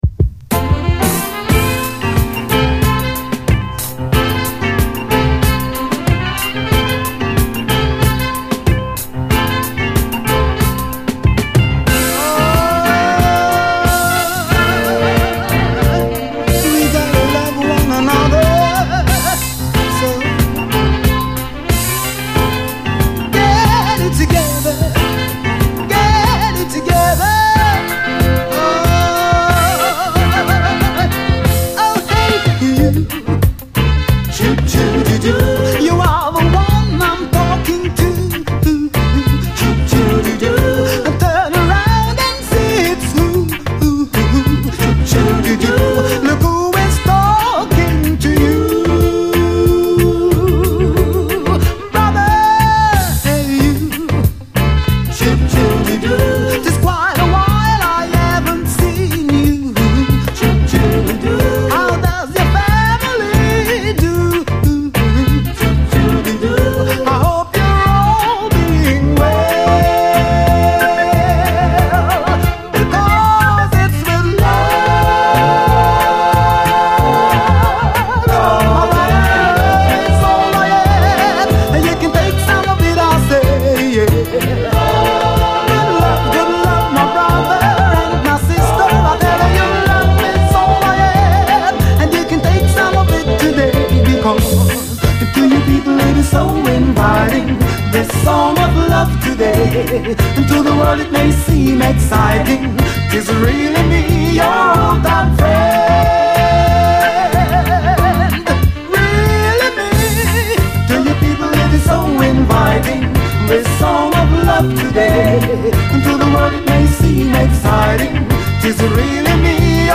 REGGAE
喜びが爆発する天下一品のキラー・ルーツ・ステッパー
これぞソウルフル・ルーツ・レゲエ最高峰！